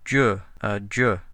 Italian MLS (Low Quality)
A multi-speaker model for Italian based on the MLS dataset.
11 d͡ʒ consonant affricate post-alveolar voiced [
voiced_postalveolar_affricate.wav